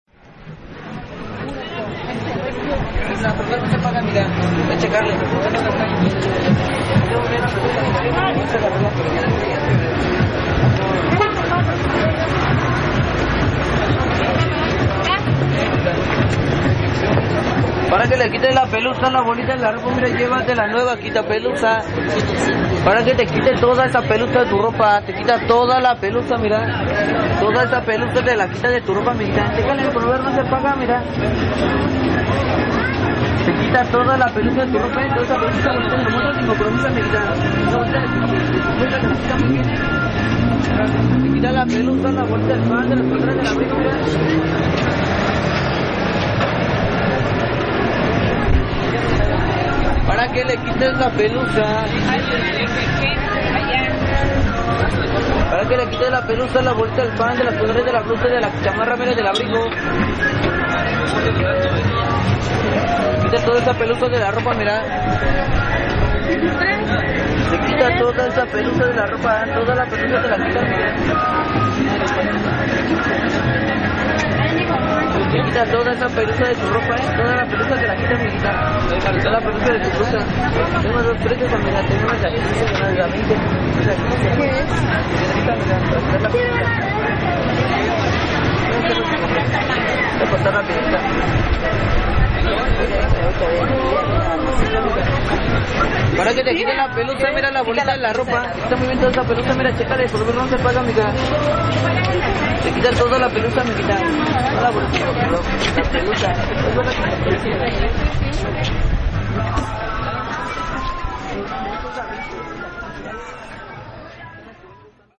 En la cotidianidad del centro de mi ciudad uno puede encontrar una variedad infinita de posibilidades visuales y sonoras. Esa tarde encontré a un merolico ofreciendo con esmero un “quita pelusa”, captando la atención de los transeuntes.